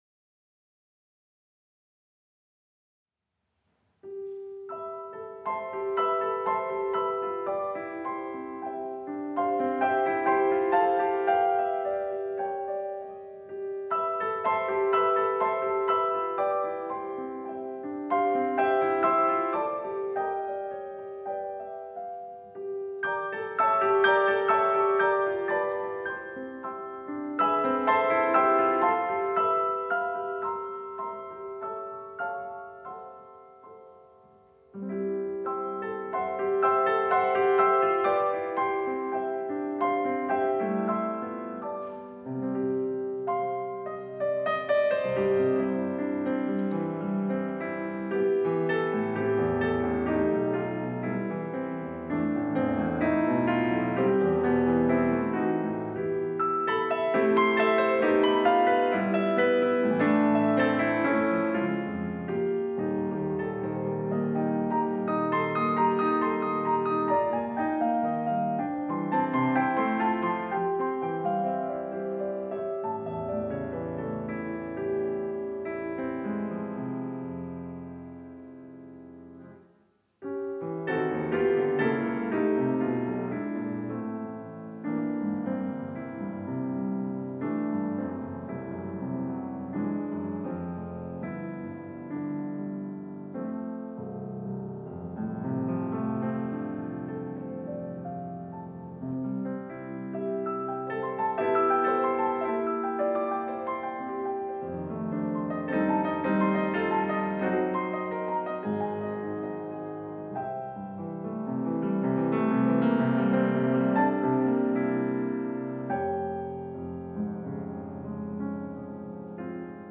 Venue: St. Brendan’s Church Duration
pf Instrumentation Category:Solo Artists
Piano